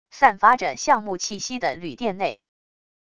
散发着橡木气息的旅店内wav音频